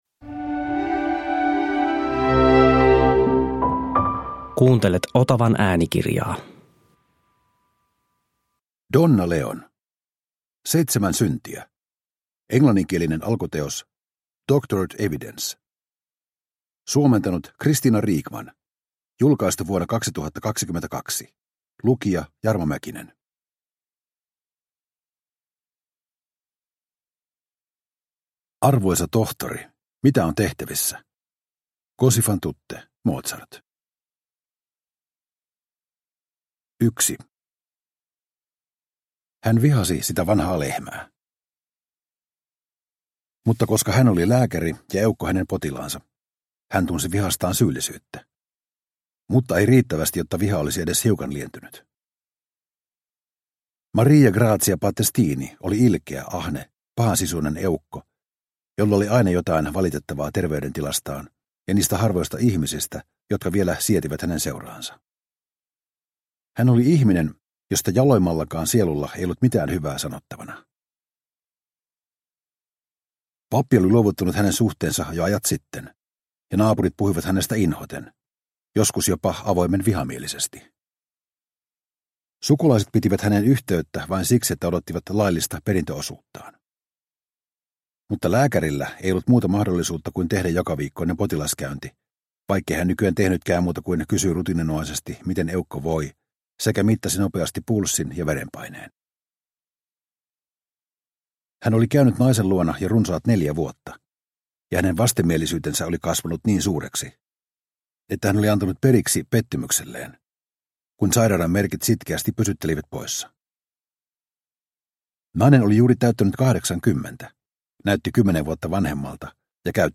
Seitsemän syntiä – Ljudbok – Laddas ner